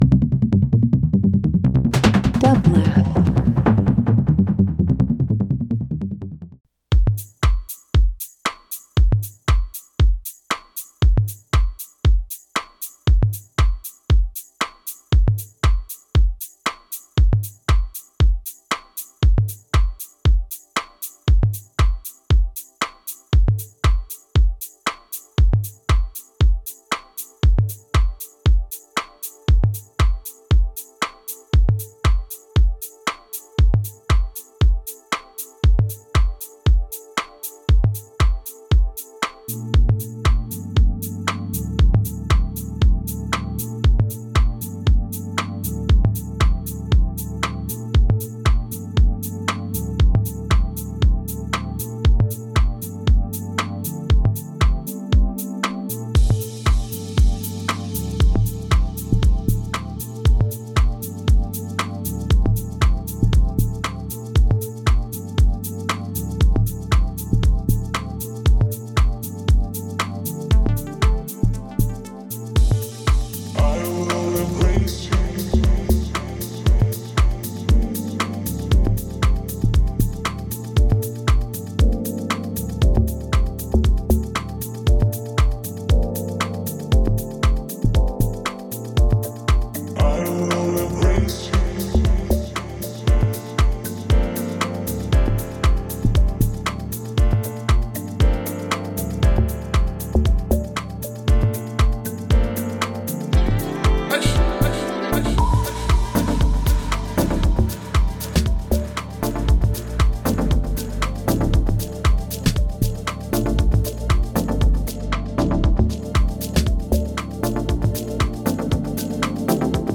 House, Salsa, Cumbia, and more
Cumbia Dance House Latin